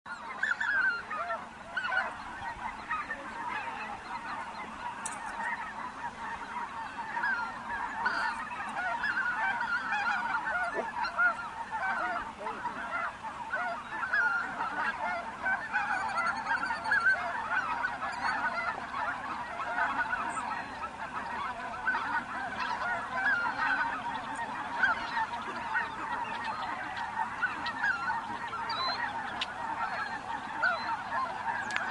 Snow Geese Bouton sonore